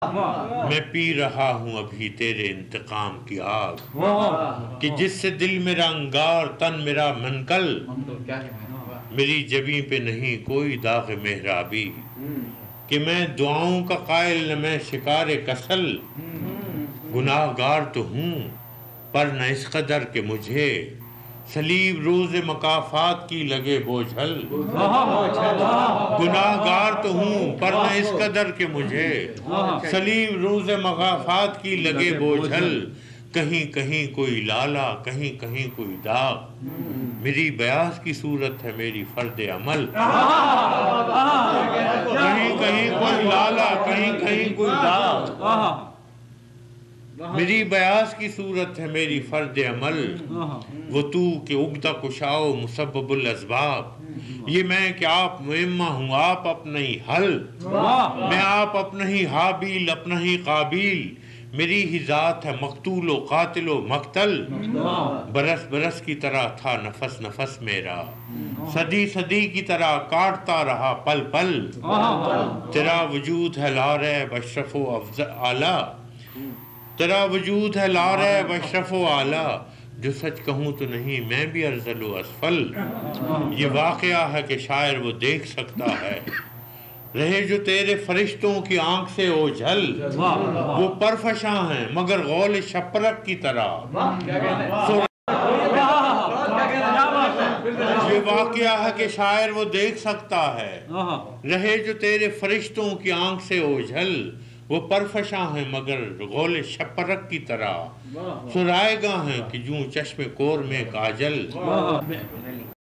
It is posted on youtube as a recitation by faraz at a private gathering.